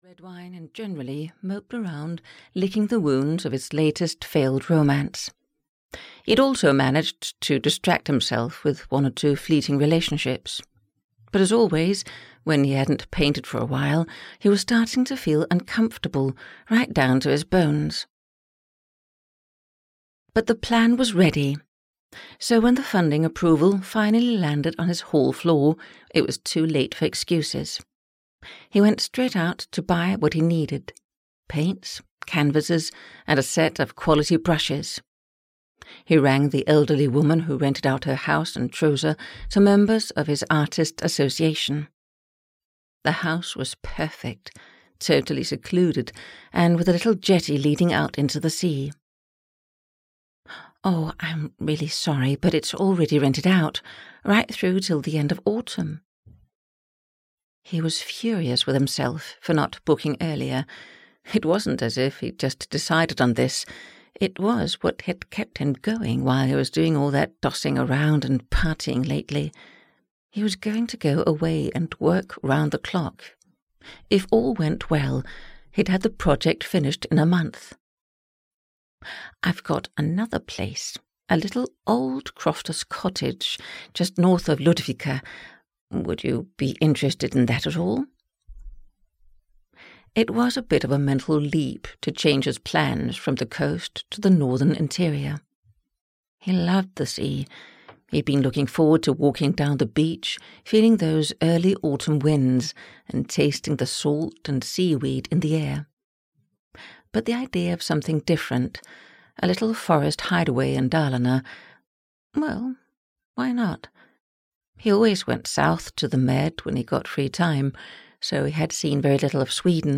Three Days in September (EN) audiokniha
Ukázka z knihy